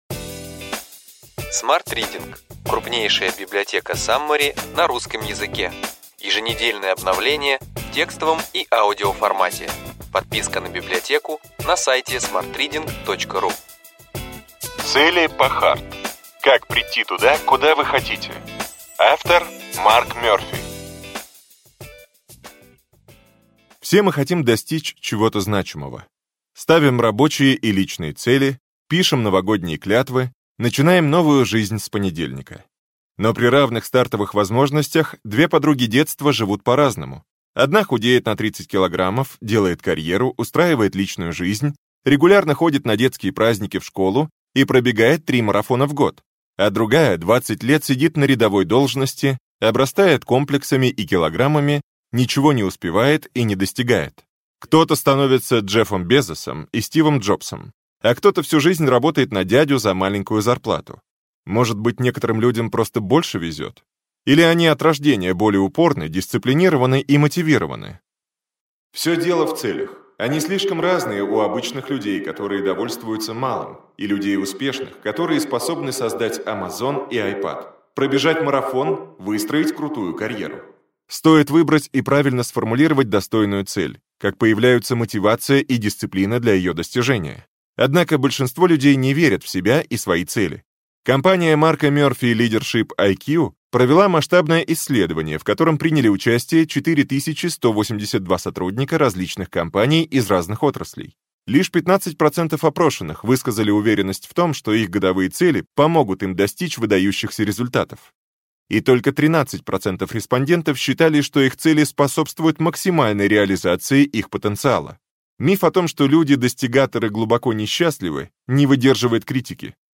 Аудиокнига Ключевые идеи книги: Цели по HARD. Как прийти туда, куда вы хотите.